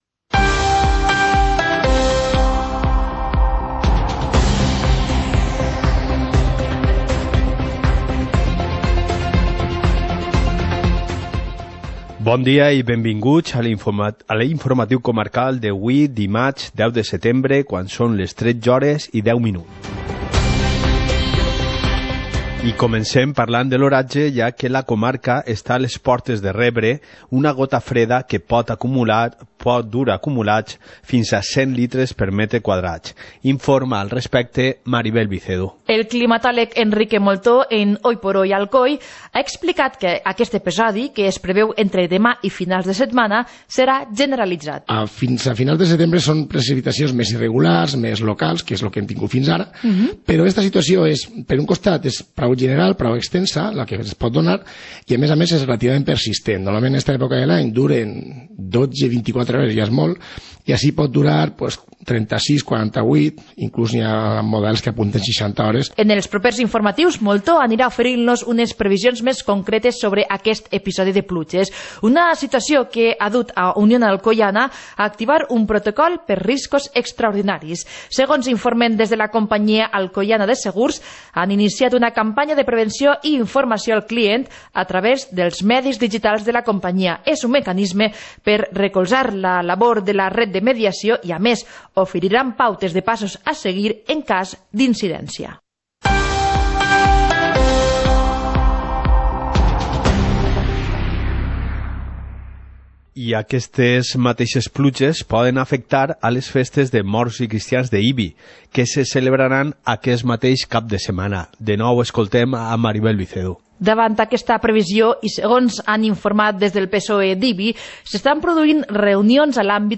Informativo comarcal - martes, 10 de septiembre de 2019